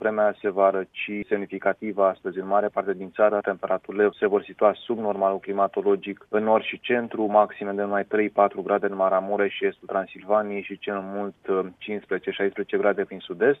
Ne aşteaptă o perioadă cu temperaturi mai scăzute, ploi şi ninsori – în zonele înalte de munte. Cum va fi vremea astăzi – ne spune meteorologul de serviciu